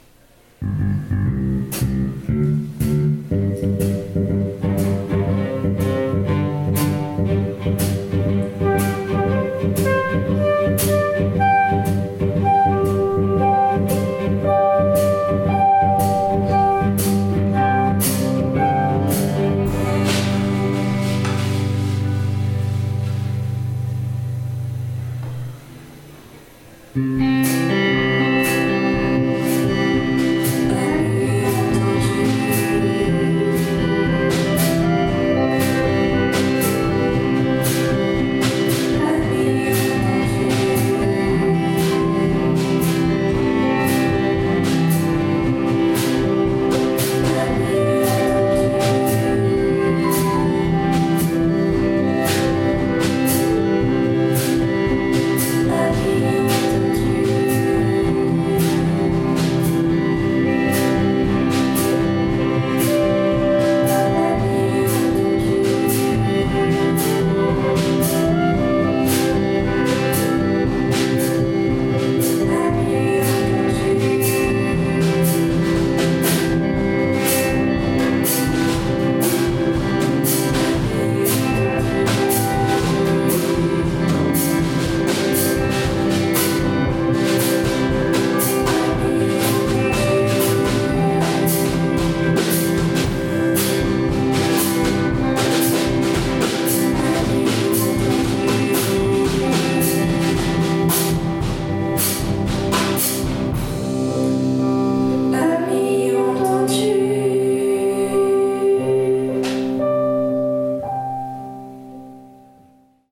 En cette semaine commémorative de l'armistice du 11 novembre, les 1ères et terminales de spécialité-musique vous partagent leur dernier enregistrement revisitant avec poésie et légèreté l'hymne de la Marseillaise et celui de l'Ode à la joie.